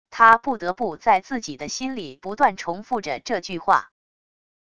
他不得不在自己的心里不断重复着这句话wav音频生成系统WAV Audio Player